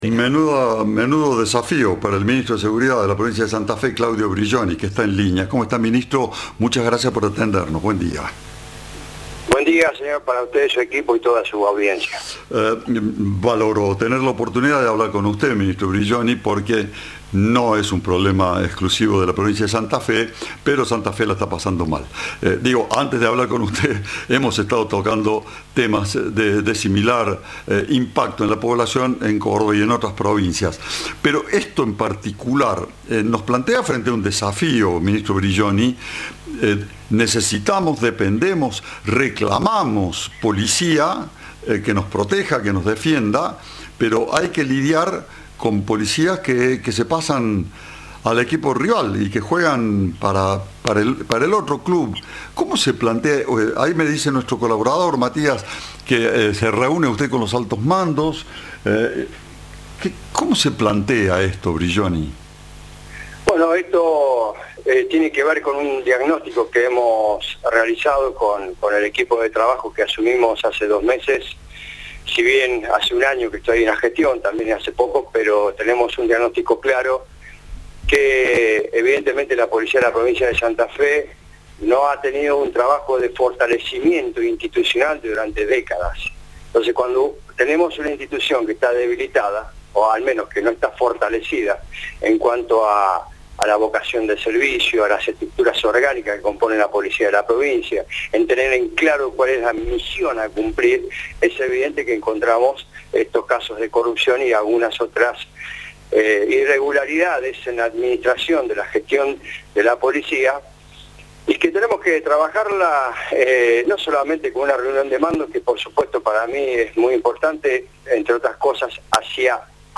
En diálogo con Cadena 3, Claudio Brilloni, dijo que “la policía de la provincia de Santa Fe no ha tenido un fortalecimiento institucional durante décadas y en cuanto a la vocación de servicio en las instituciones orgánicas encontramos estos casos de corrupción y de irregularidades”.